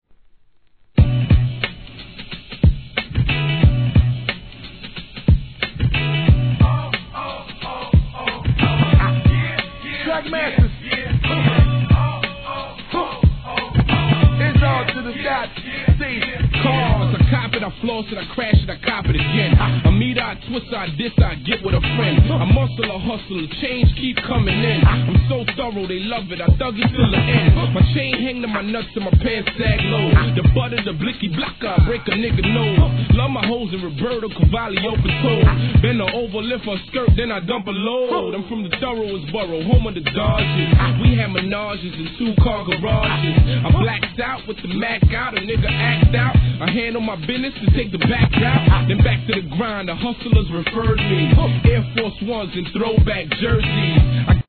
HIP HOP/R&B
シンプルなギターLOOPトラックながらテンション上がります!